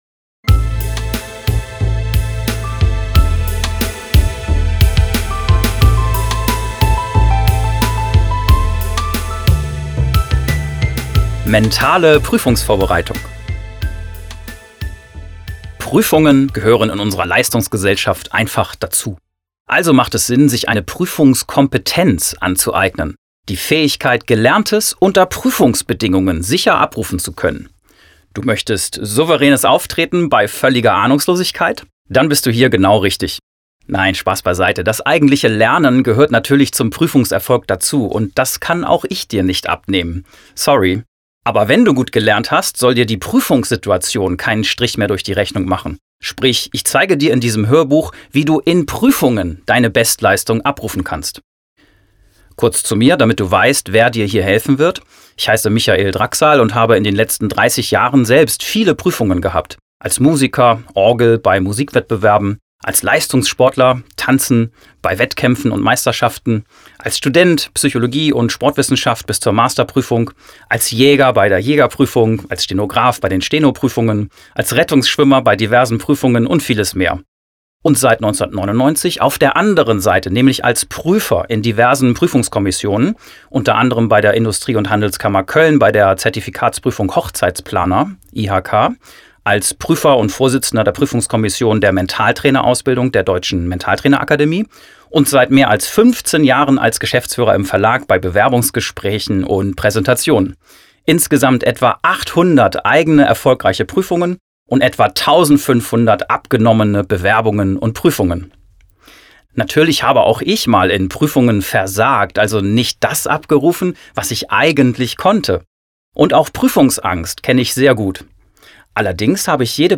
Prüfungen so erfolgreich meistern wie Olympiasieger – DAS HÖRBUCH